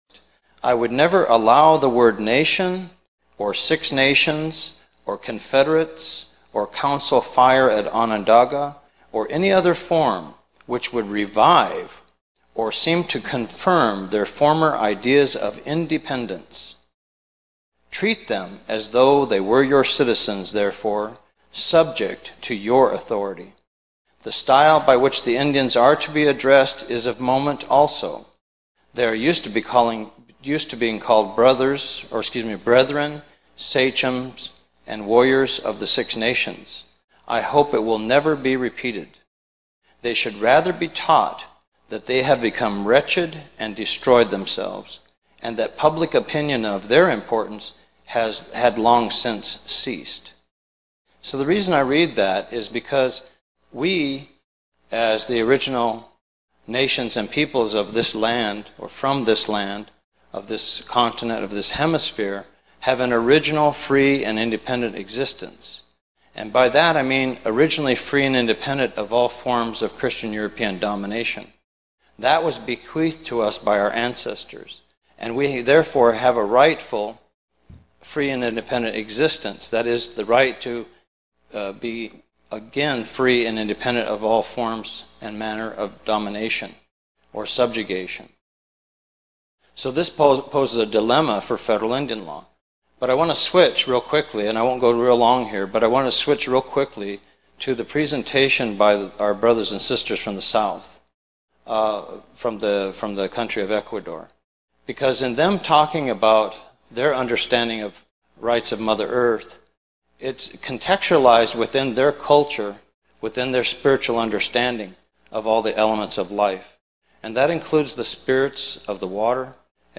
Rights of Mother Earth Intl Indigenous Conference, 6 Apr 2012
Rights of Mother Earth: Restoring Indigenous Life Ways of Responsibility and Respect. International Indigenous Conference April 4-6, 2012 held at Haskell Indian Nations University, Lawrence Kansas.